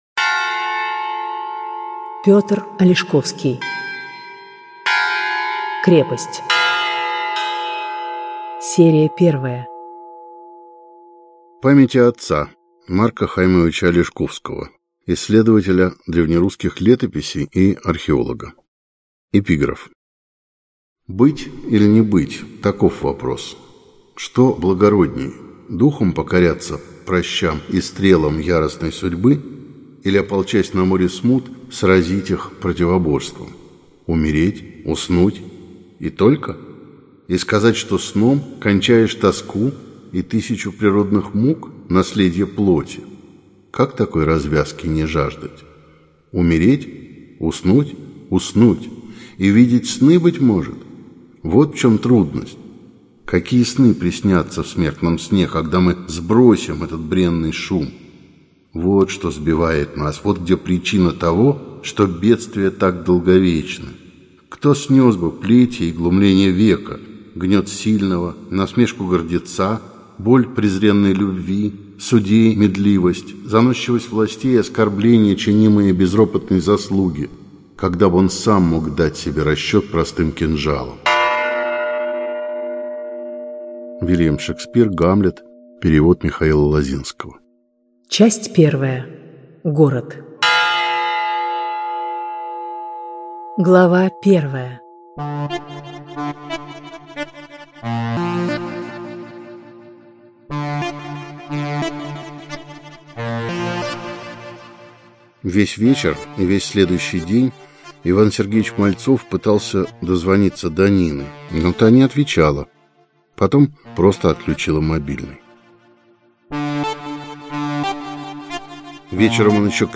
Аудиокнига Крепость | Библиотека аудиокниг